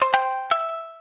shamisen_cge.ogg